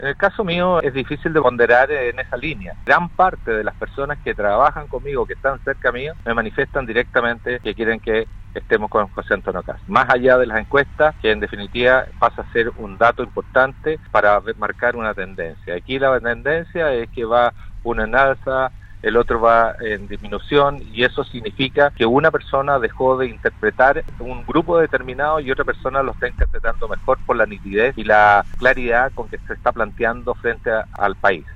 En entrevista con radio Sago, el parlamentario que acumula cinco períodos consecutivos en la Cámara, indicó que hace ocho años intentó que el propio Kast fuera candidato a senador por la región, además de compartir banca en el último período como diputado del candidato de extrema derecha. Hernández aseguró que no hay contradicciones en su apoyo al representante del Partido Republicano, pese a que la primaria de su sector fue ganada por Sebastián Sichel, ex DC y ahora independiente.